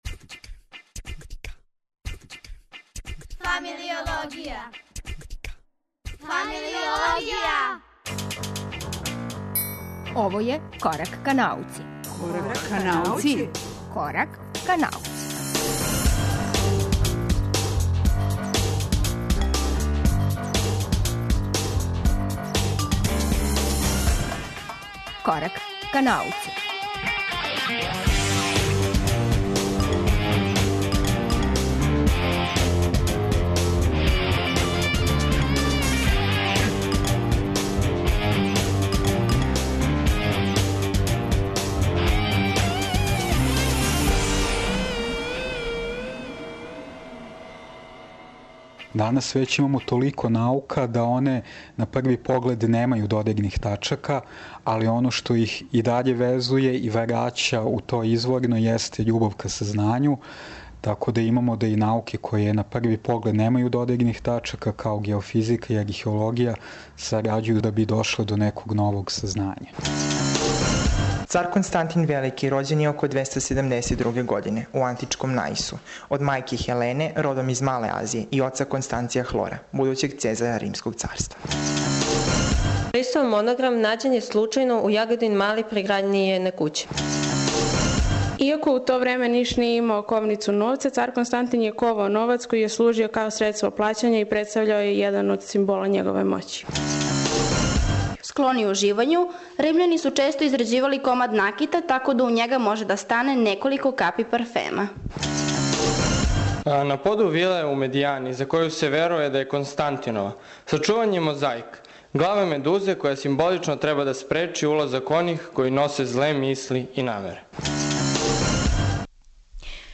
Данас се 'Корак ка науци' реализује са архолошког локалитета Медијана крај Ниша.